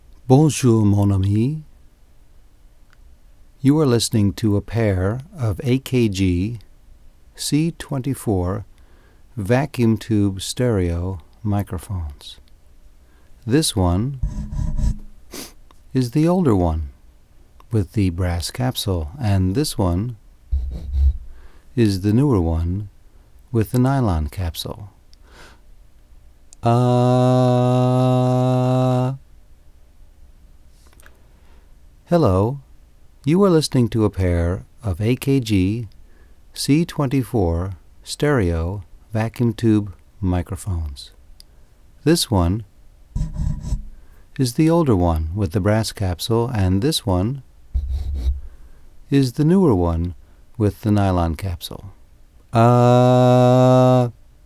AKG C24 Stereo Vacuum Tube (valve) microphone evaluation.
Comparison of t two "identical" mics of different vintage and capsules.
Great River transformerless mic preamp to Sony PCM-R500 RDAT.
Vintage BRASS CK-12 capsule (Left Channel, lower mic in the image below) and "Reissue" Nylon capsule (right channel, upper mic in the image below).
AKG_C24_stereo_BrassLeft_NylonRight.wav